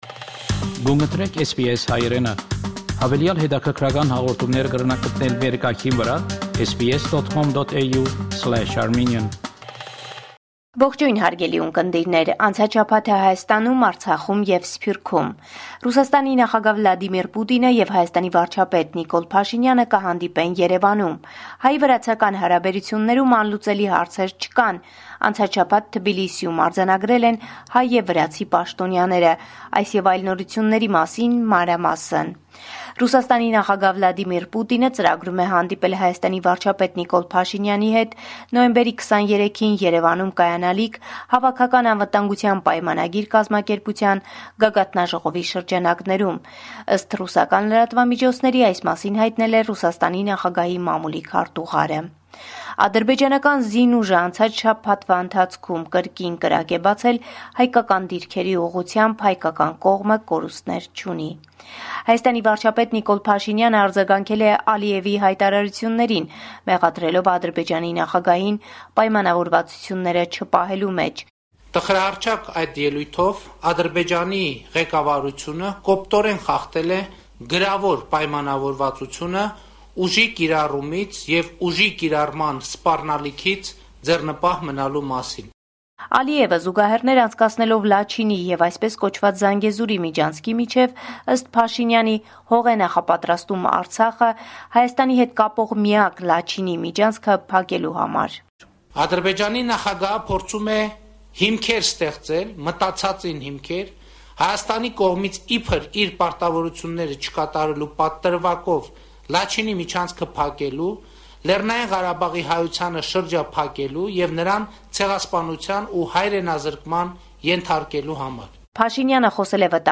Latest News from Armenia – 22 November 2022